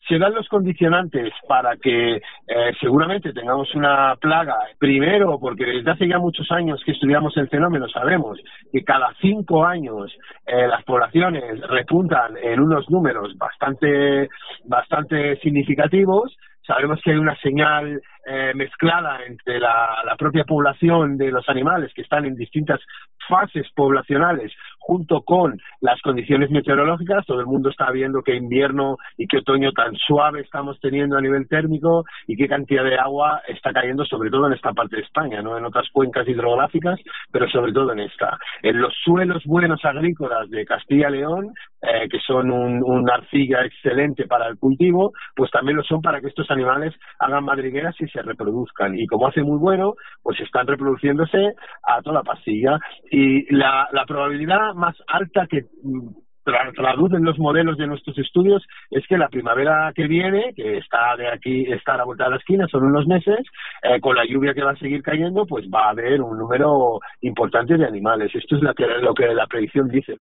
profesor de Zoología